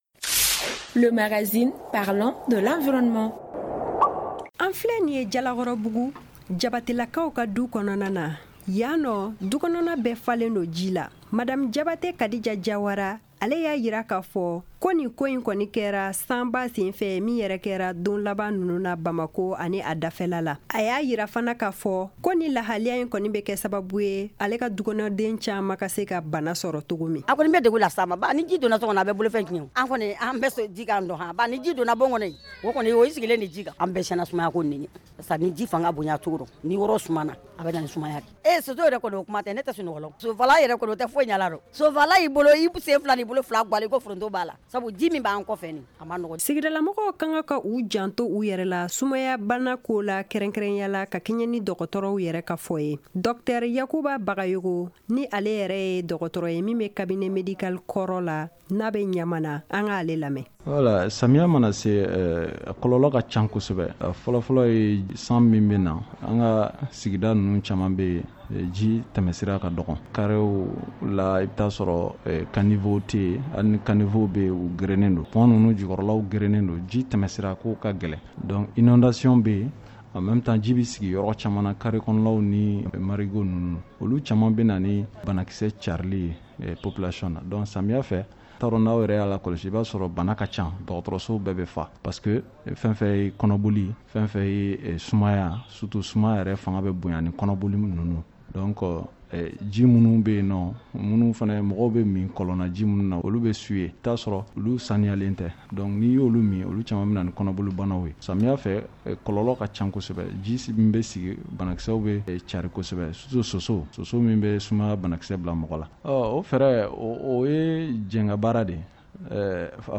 Magazine en bambara: Télécharger